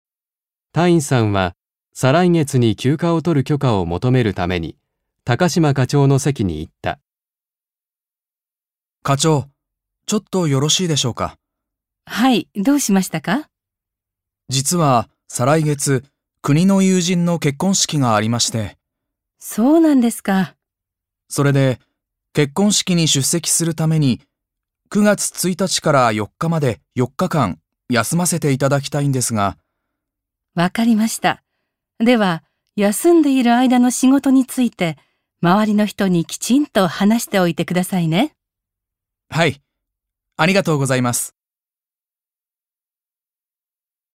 1.1 会話（許可きょかもとめる・許可きょかをする① ）